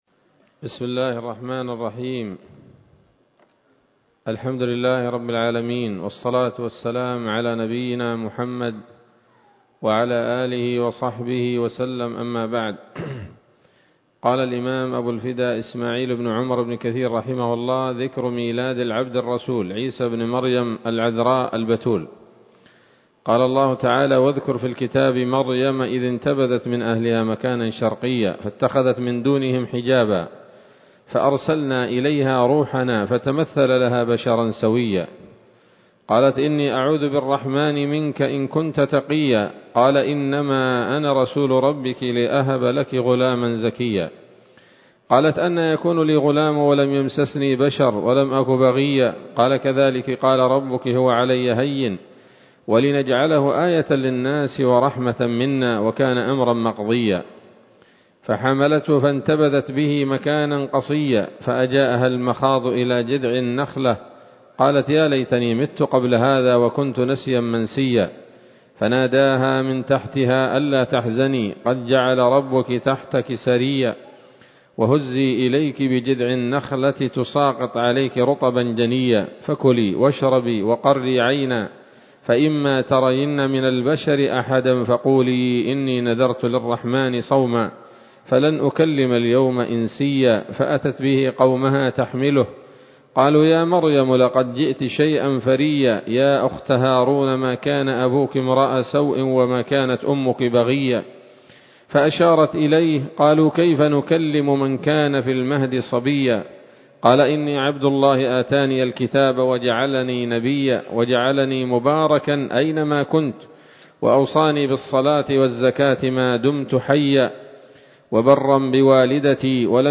‌‌الدرس الحادي والأربعون بعد المائة من قصص الأنبياء لابن كثير رحمه الله تعالى